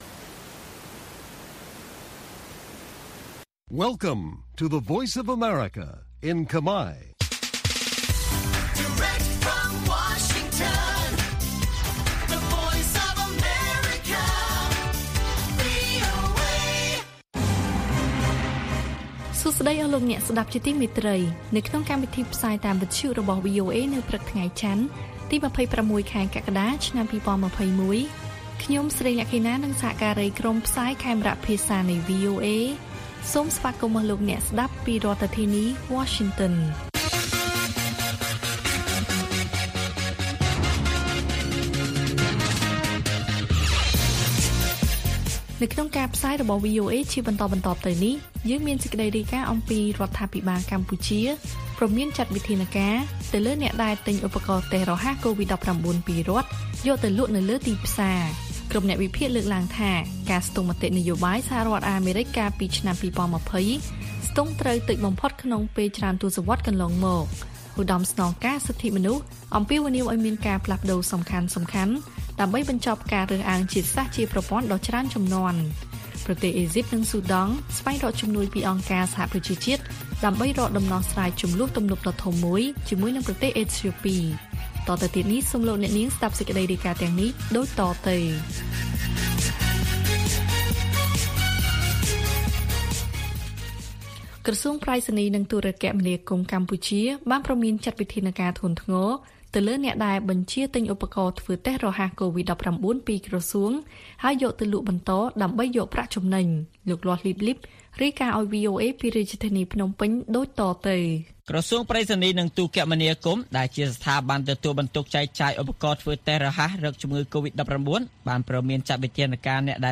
ព័ត៌មានពេលព្រឹក៖ ២៦ កក្កដា ២០២១